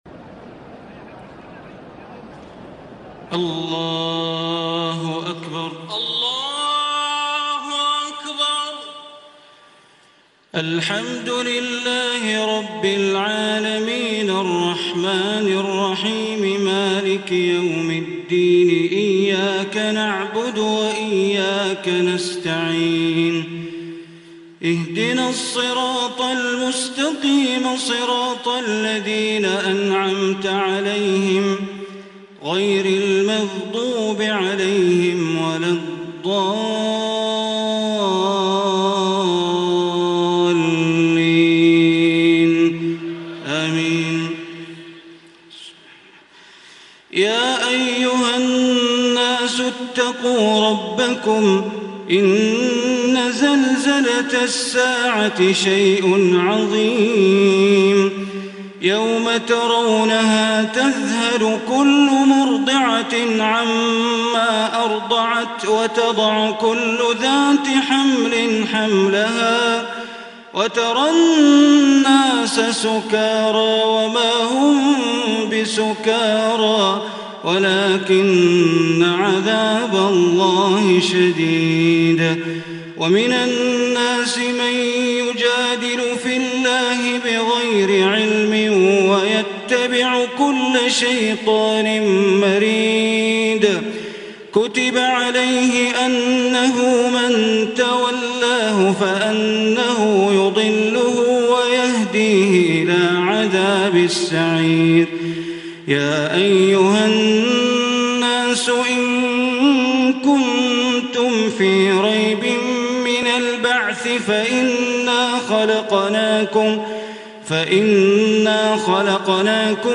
Listen very beautiful Recitation / Tilawat of Surah Hajj in the superb voice of Sheikh Bandr Baleela.
Sheikh Bandr Baleela has recited this surah in Ramadhan in Haram Makkah.